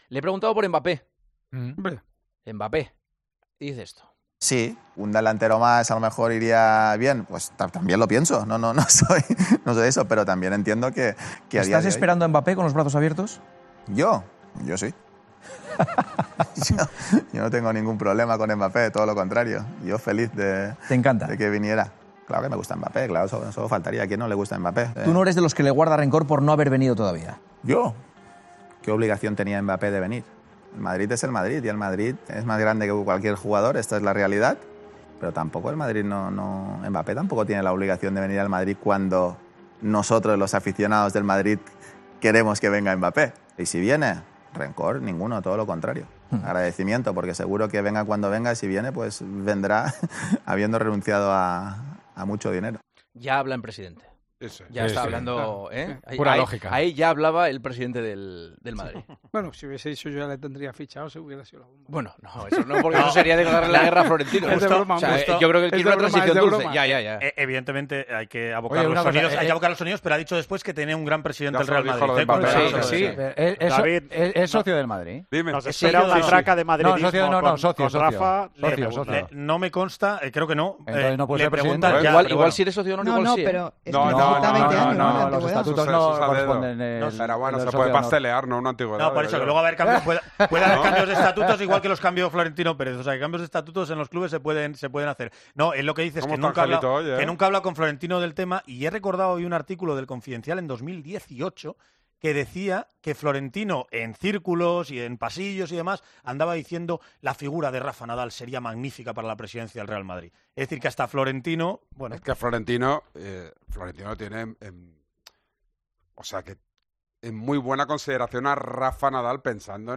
ESCUCHA EL DISCURSO DEL 'PRESIDENTE' NADAL Y LA REACCIÓN DE JUANMA CASTAÑO EN EL PARTIDAZO DE COPE